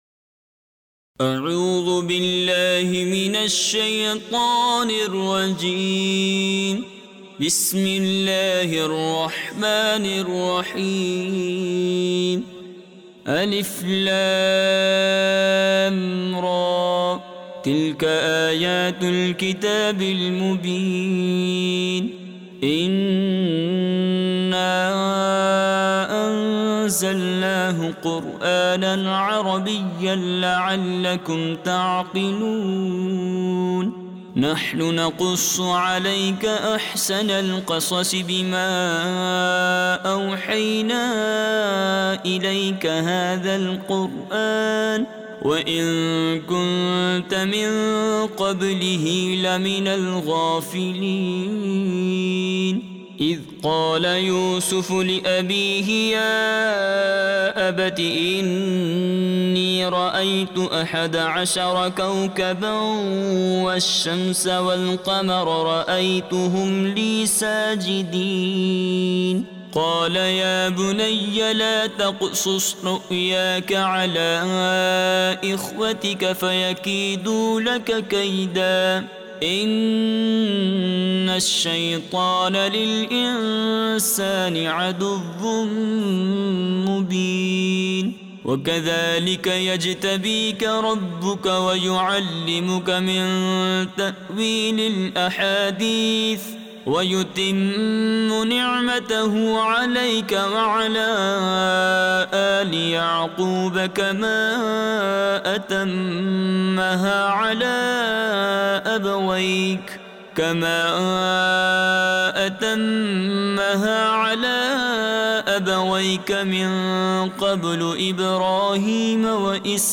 From this page, you can read Surah Yusuf Arabic and listen to Surah Yusuf online mp3 audio recitations with Urdu and English translations for better understanding.